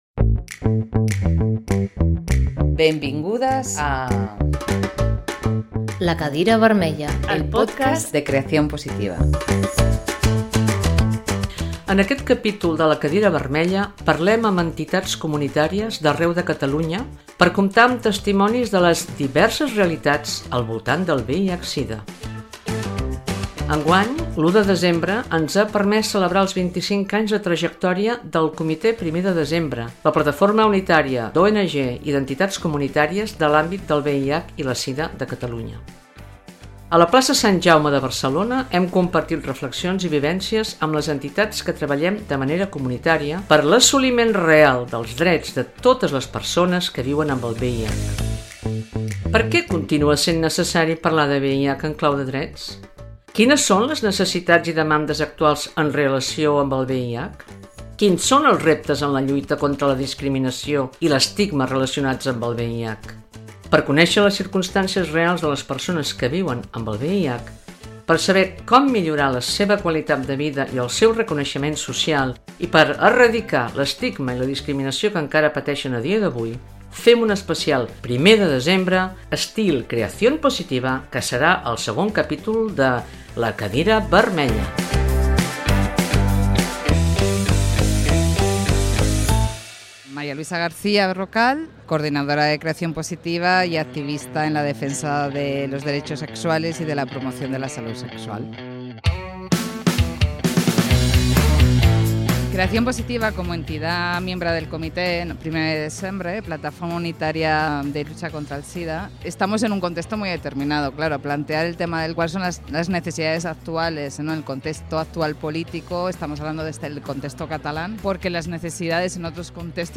Con capítulos de una hora más o menos, el equipo de Creación Positiva os presentamos un nuevo formato, que es el paso natural de nuestra línea editorial, con tertulias, debates, entrevistas y secciones específicas en las que reflexionamos sobre los Derechos Sexuales, el placer, la salud sexual, el VIH y las Violencias Sexuales.